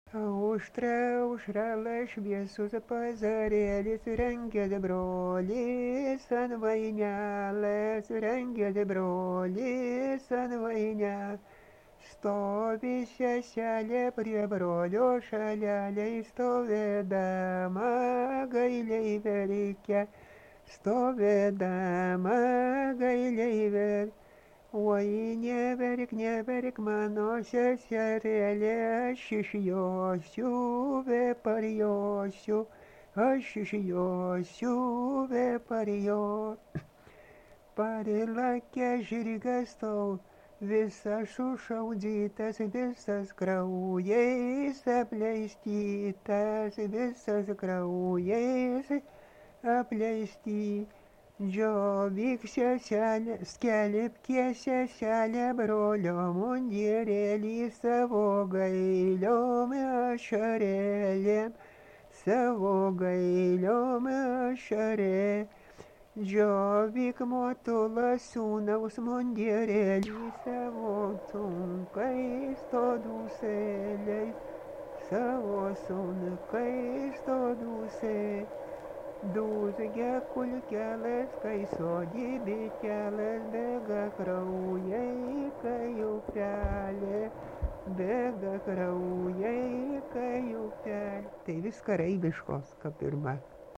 Dalykas, tema daina
Atlikimo pubūdis vokalinis